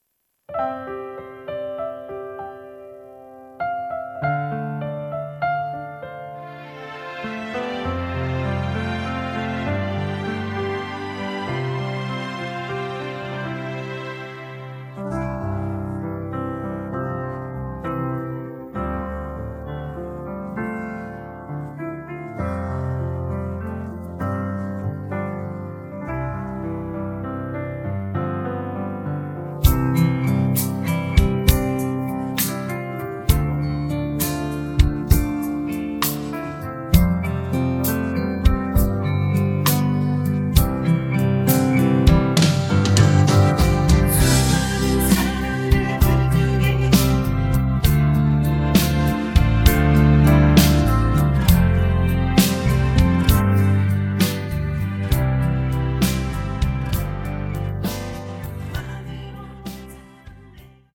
음정 -1키 3:44
장르 가요 구분 Voice MR
보이스 MR은 가이드 보컬이 포함되어 있어 유용합니다.